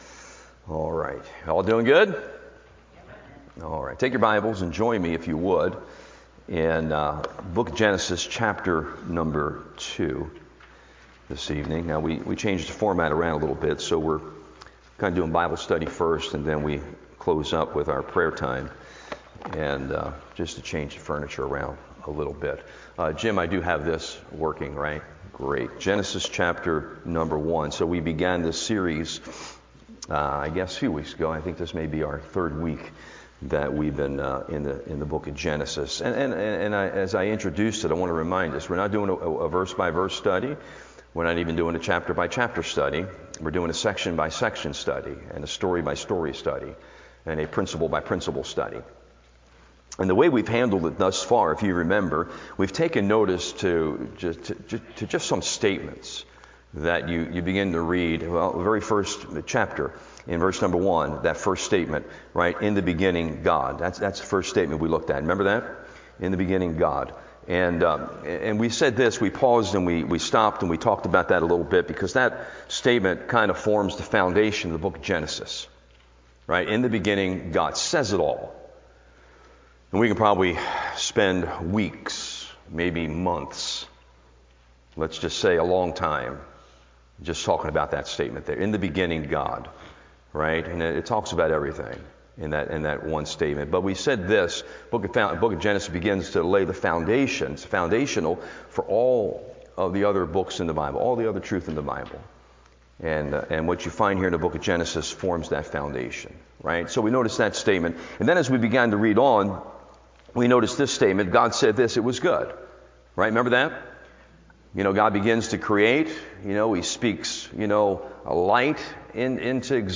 Genesis Series Wed Night Bible Study